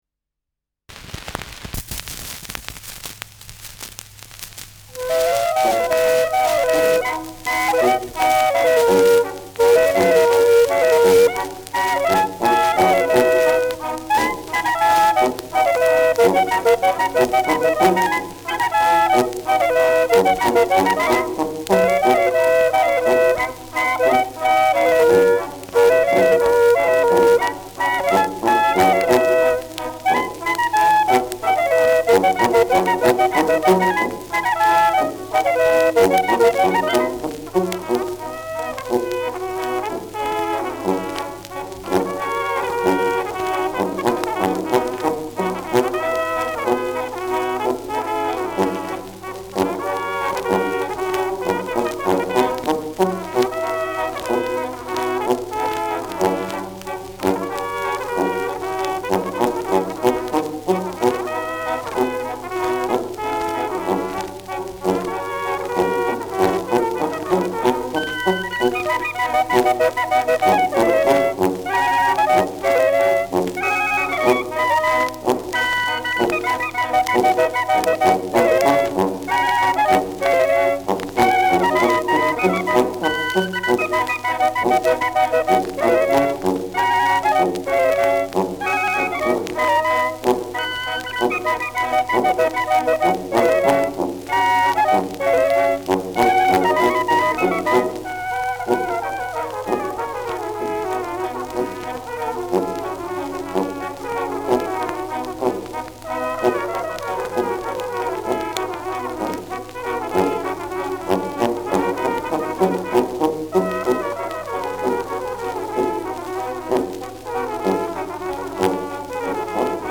Schellackplatte
Leicht abgespielt : Gelegentlich stärkeres Knacken
Maxglaner Bauernkapelle, Salzburg (Interpretation)